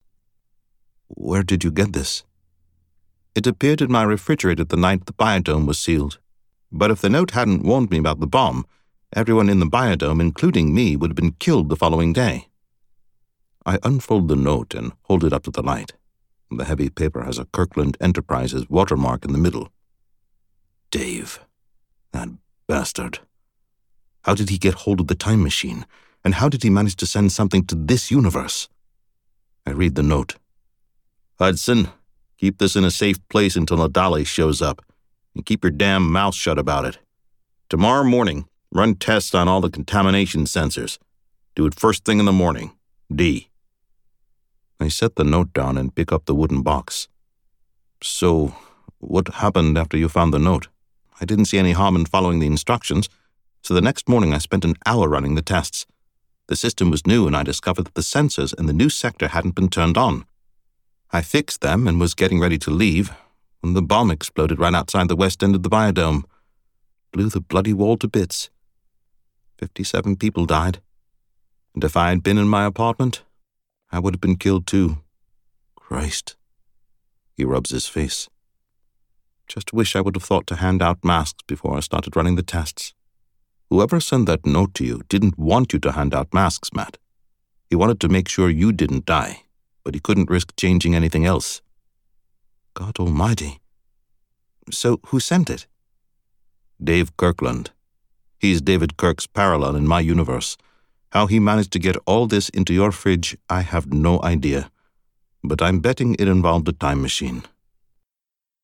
Accent Capabilities: American Regional (Southern/Western, New England, New York, California Surfer, Mobster), International (British, Irish, Scottish, Italian, French, German, Eastern European, Generic Middle-Eastern, Generic Asian, Australian). Fantastic at making up accents for Fantasy and Sci-Fi.